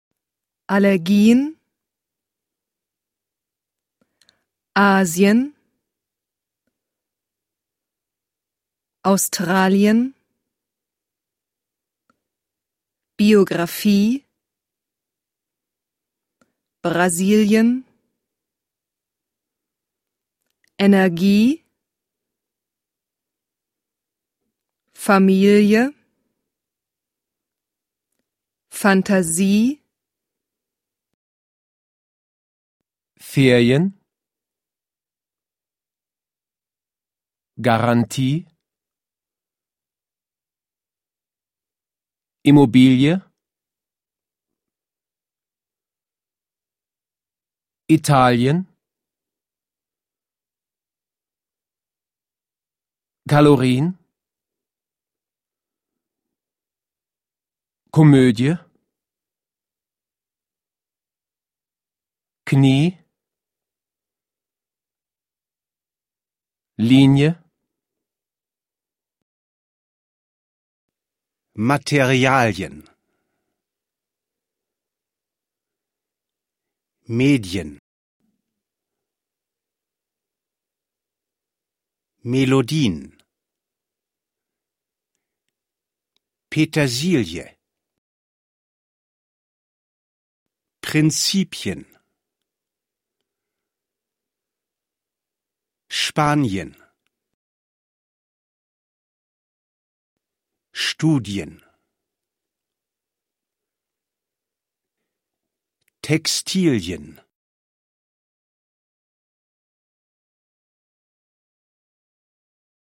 Here you will find practical exercises which will help you learn how to pronounce typical German sounds.
IEEEEEEEEEEEEEEE